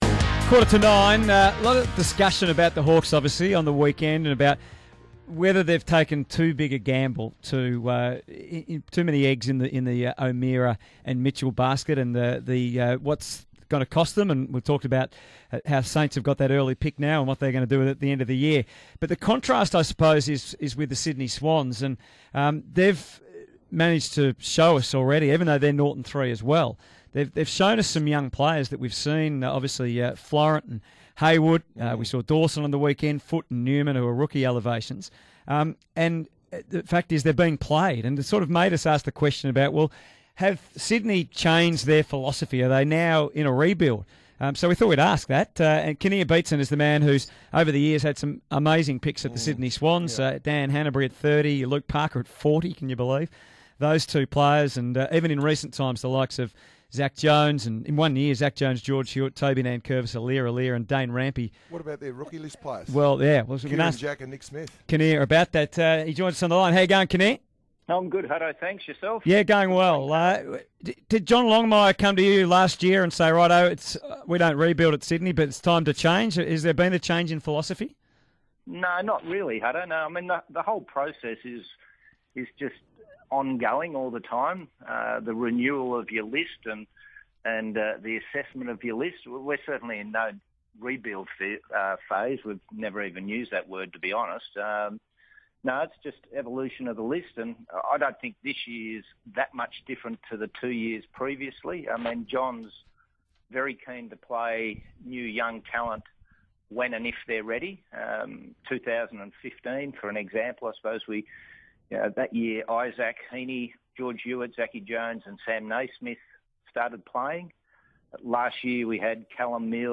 chats to the boys on SEN Breakfast.